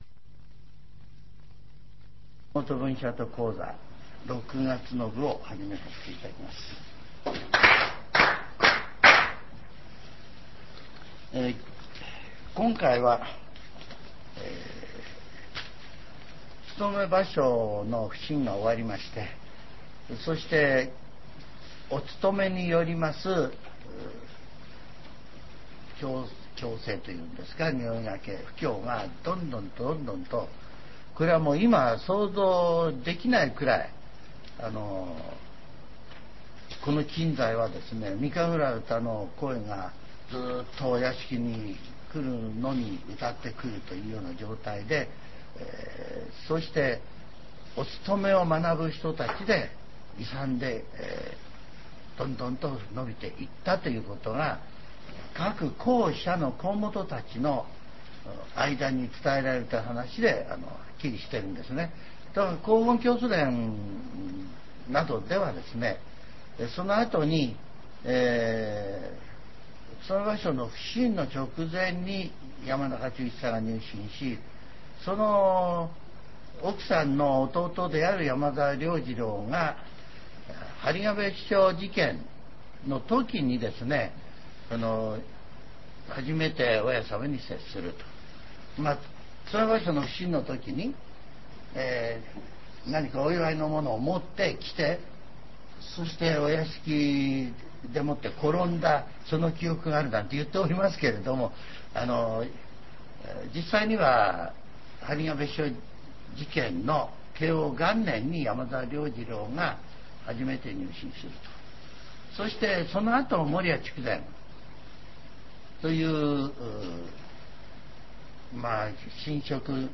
全70曲中22曲目 ジャンル: Speech